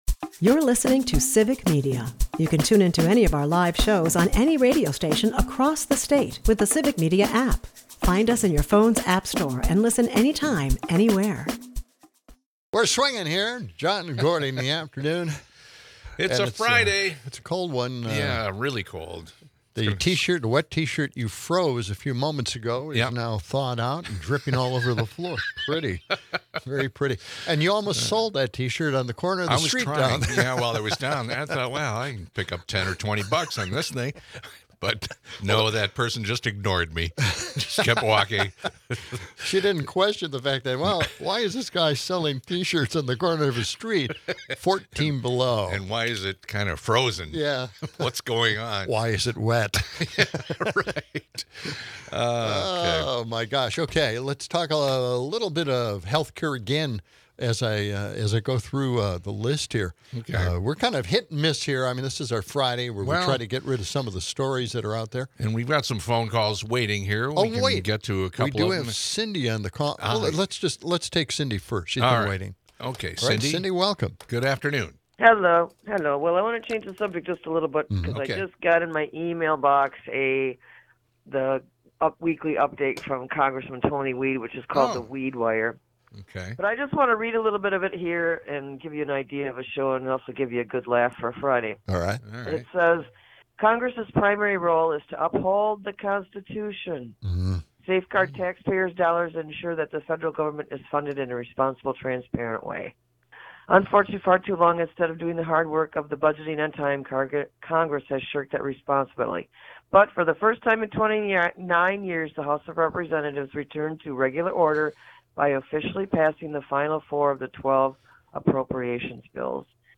The hosts delve into Congress's budget missteps and the potential of universal healthcare, while listeners vent frustrations over ICE's aggressive tactics.